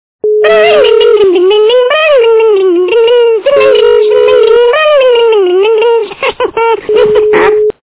- Смешные
» Звуки » Смешные » Веселый ребеночек - Имитация звонка мобильника
При прослушивании Веселый ребеночек - Имитация звонка мобильника качество понижено и присутствуют гудки.